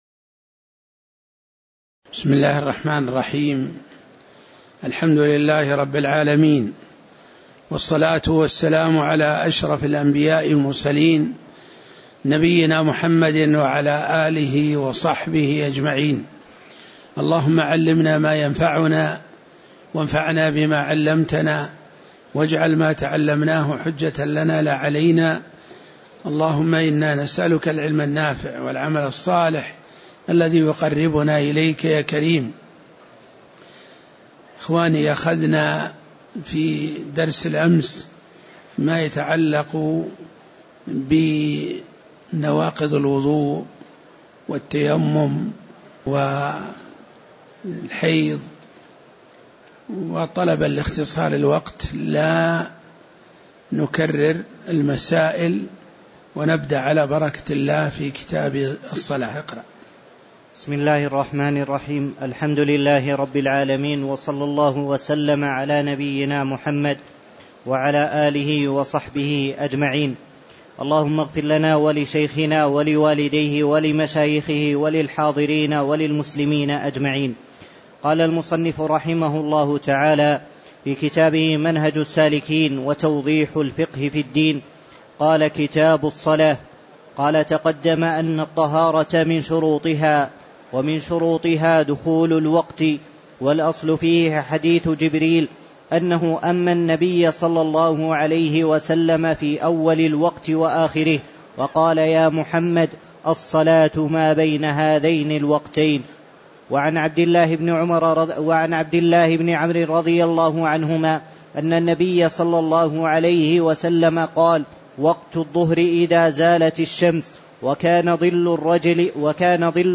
تاريخ النشر ١٤ شوال ١٤٣٧ هـ المكان: المسجد النبوي الشيخ